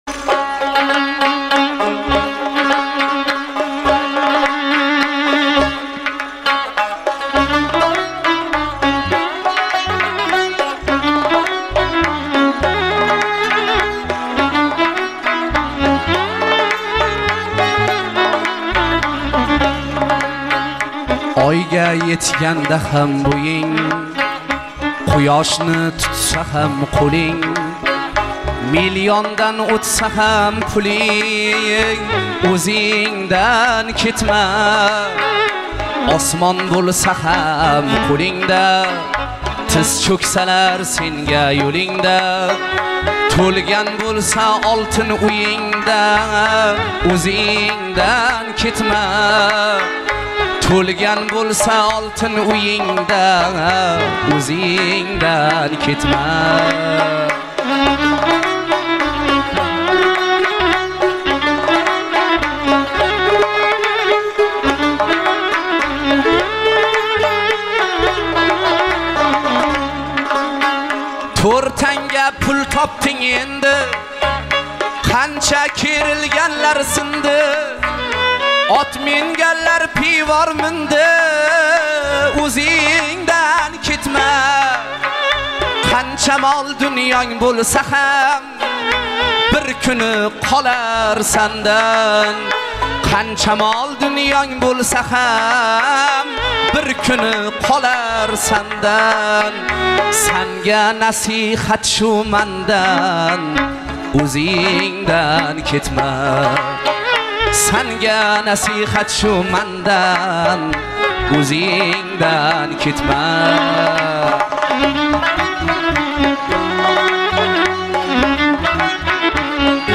jonli ijro